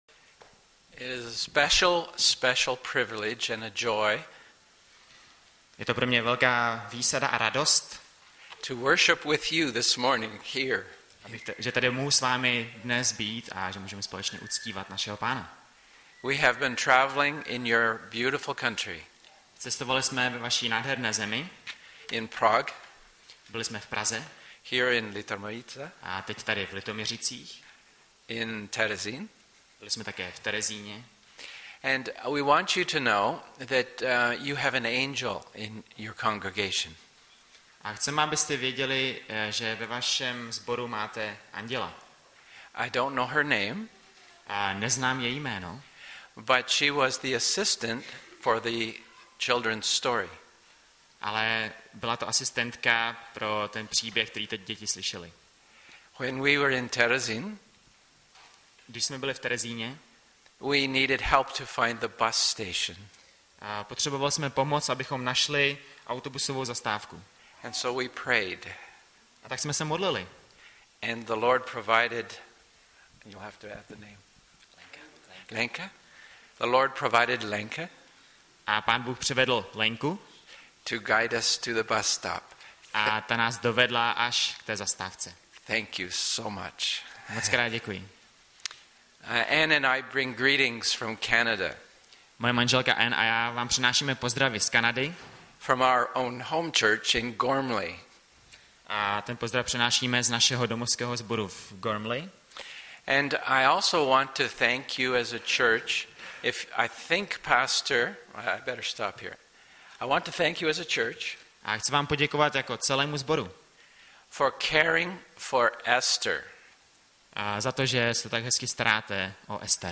Hlavní nabídka Kázání Chvály Kalendář Knihovna Kontakt Pro přihlášené O nás Partneři Zpravodaj Přihlásit se Zavřít Jméno Heslo Pamatuj si mě  06.03.2016 - JE KRISTUS STŘEDEM TVÉHO ŽIVOTA?